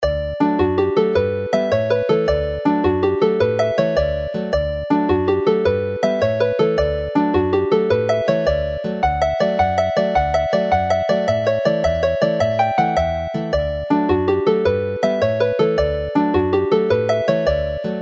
Alawon traddodiadol Cymreig - Set Hela'r Sgyfarnog, gyda Cainc Dafydd ap Gwilym a Ty a Gardd